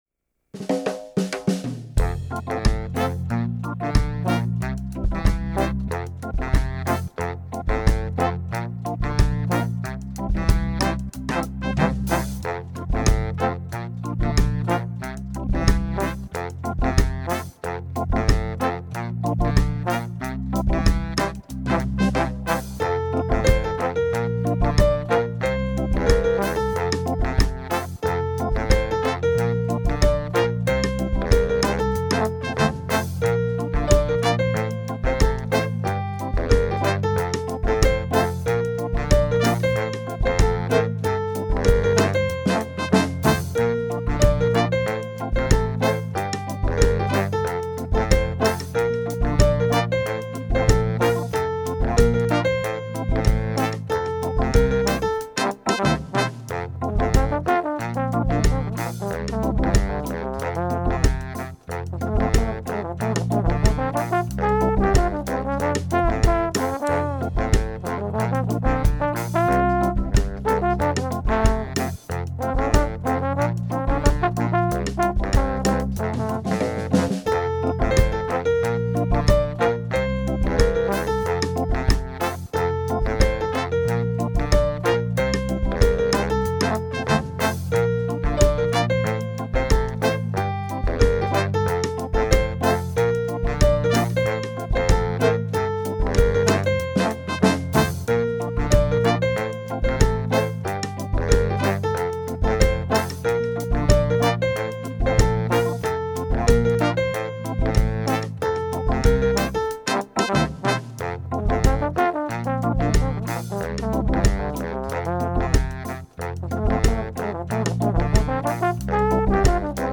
reggae
reggae.mp3